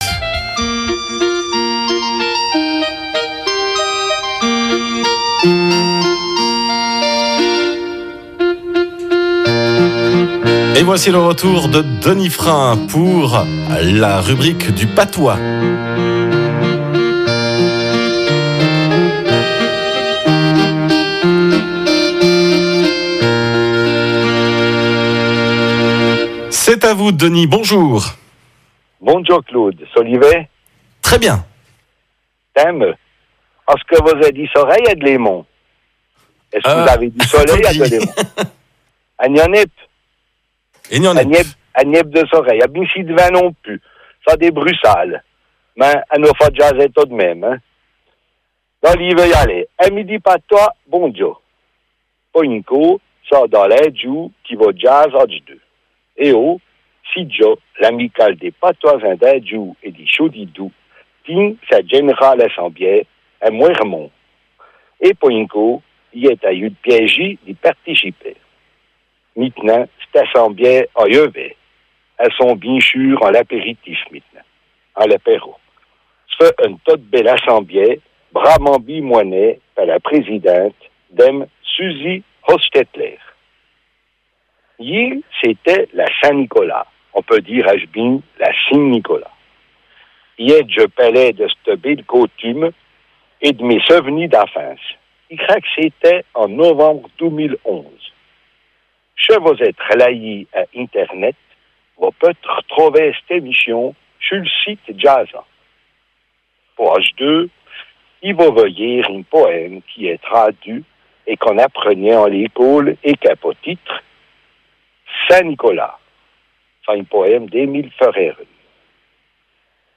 Rubrique en patois du 7 décembre 2014